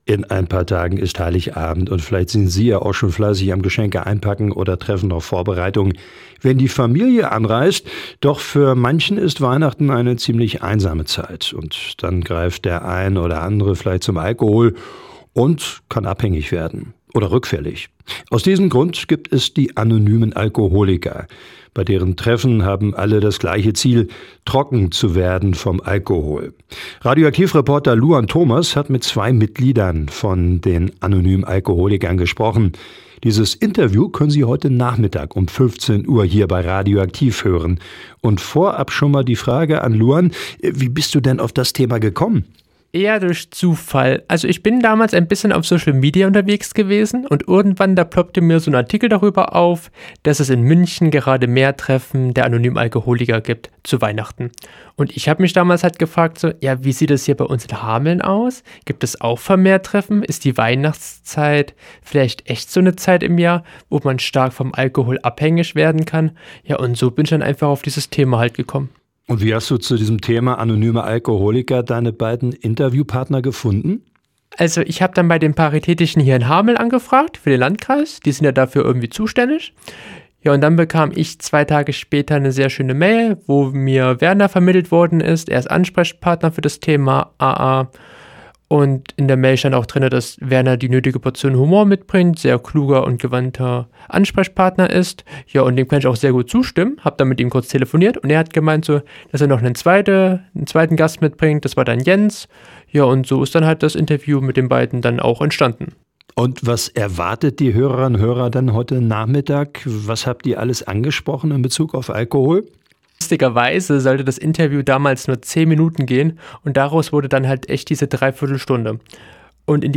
„Anonyme Alkoholiker“ im Interview bei radio aktiv, heute ab 15 Uhr
anonyme-alkoholiker-im-interview-bei-radio-aktiv-heute-ab-15-uhr.mp3